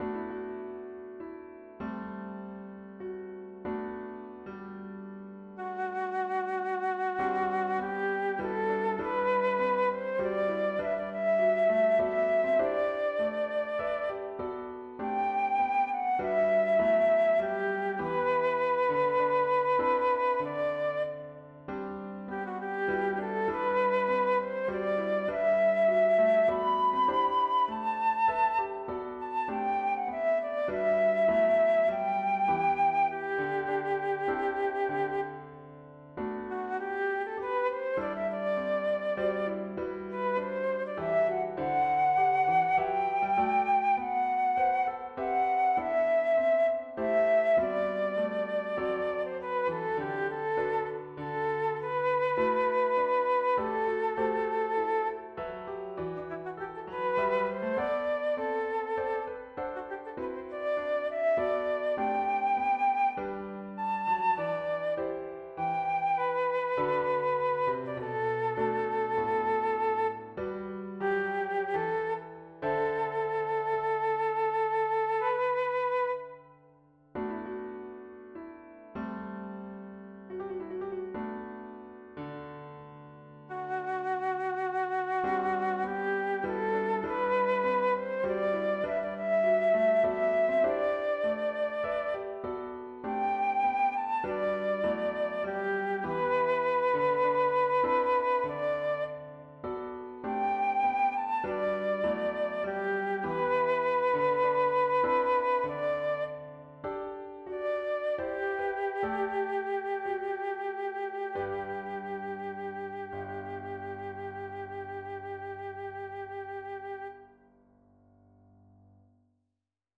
The second is also an allegretto, a melody over a four-part "hymn" tune.
2 pages, circa 2' 00" - an MP3 demo is here: